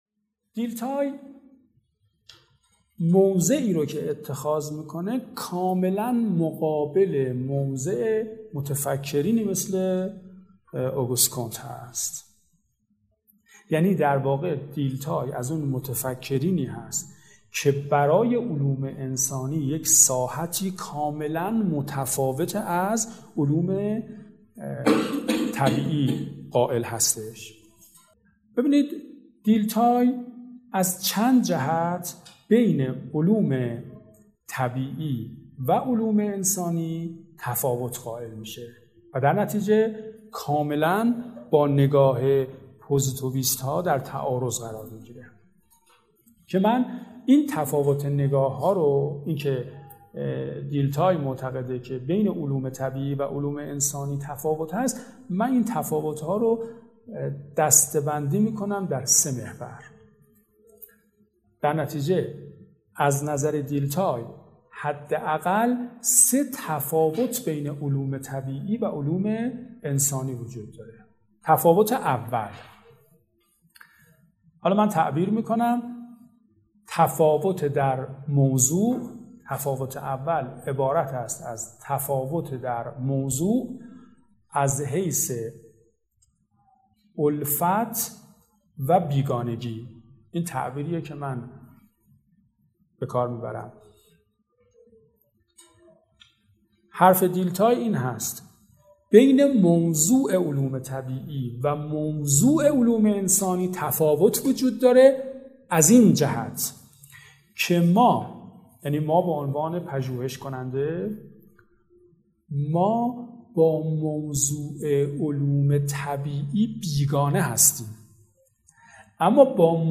به گزارش خبرگزاری مهر، این درس‌گفتار حاصل سلسله جلسات سطح دو سیزدهمین دورۀ آموزشى تربیتى «والعصر» مى باشد که در تابستان ۱۳۹۰ برگزار شده است و به همت مؤسسه علمی ـ فرهنگی سدید پس از ویرایش در اختیار علاقه‌مندان قرار مى گیرد.